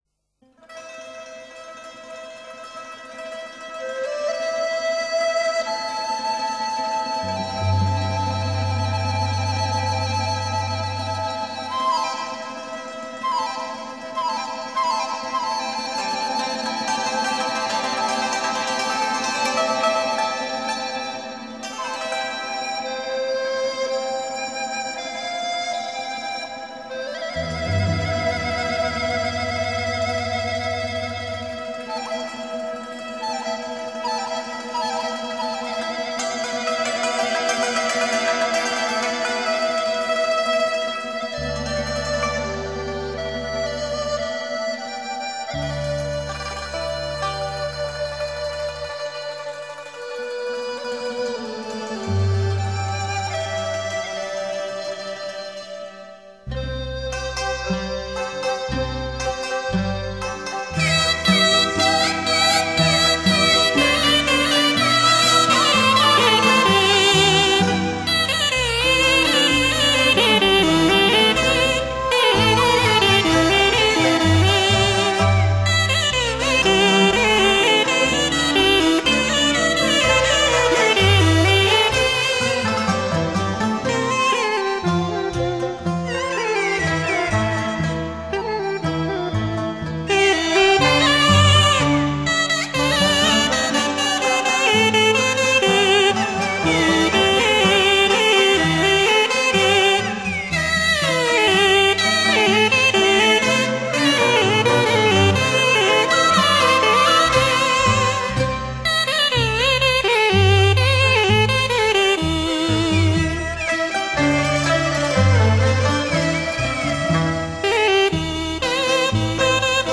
广东音乐简介:
三角洲一带的一个民间器乐曲种。